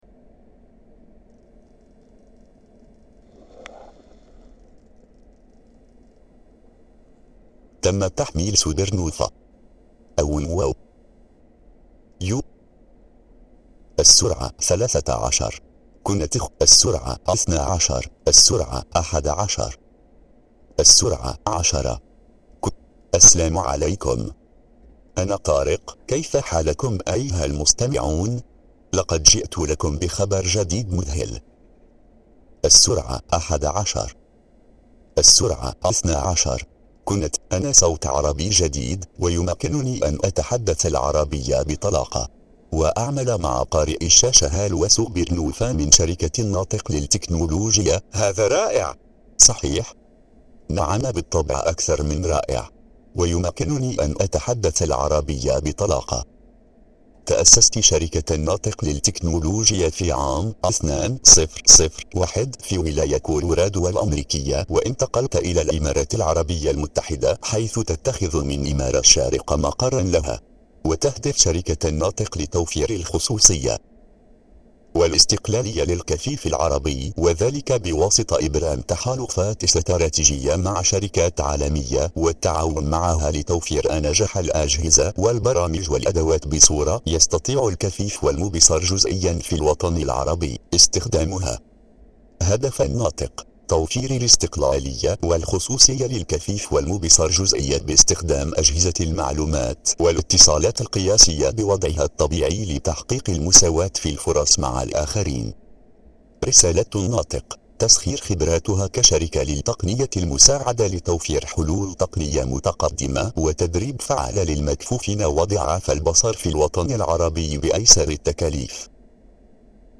تقرير سير العمل على آلة النطق الجديدة "طارق" Tariq TTS
Tariq_Loquendo_TTS_with_Supernova.mp3